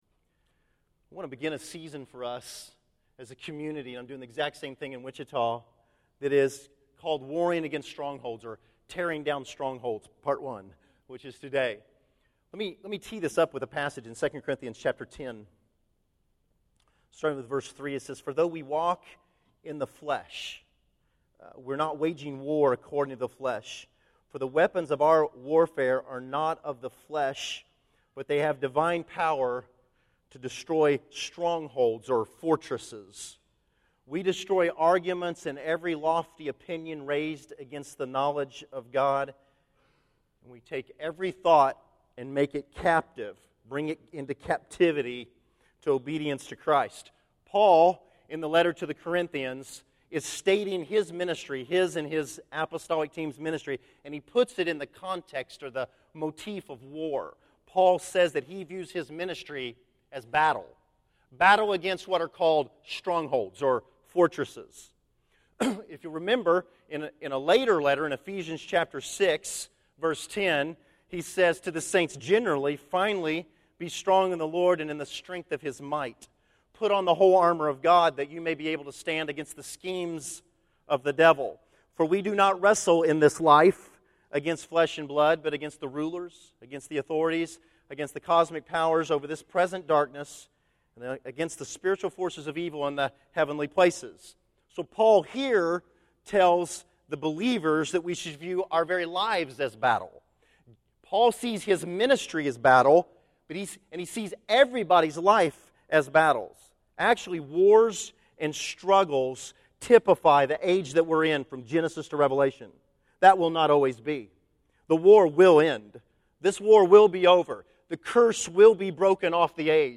Tearing Down Strongholds: Part 1 May 26, 2013 Category: Sermons | Back to the Resource Library Video Audio Part 1 of a three-part series about the battle against strongholds.